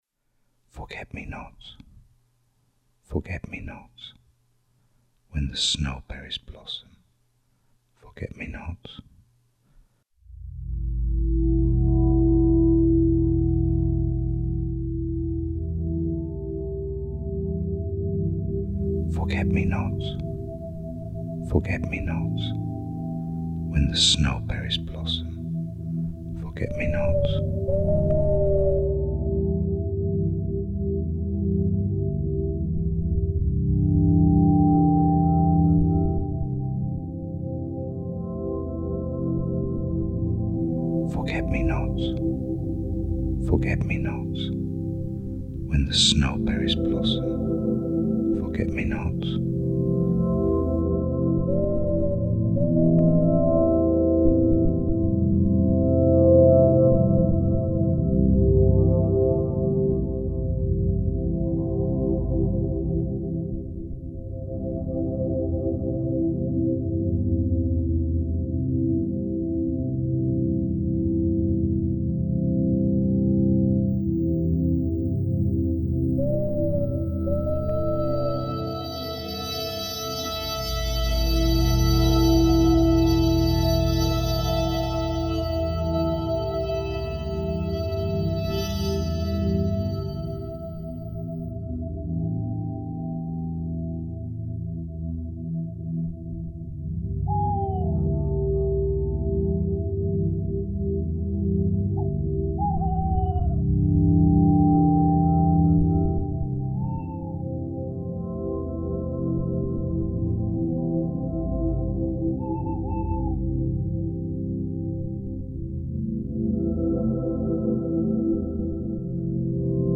Audio-Visual Installation: Blodeuwedd, 2010
Secret Light Garden, Picton Castle, Pembrokeshire, Wales
Blodeuwedd Soundscape: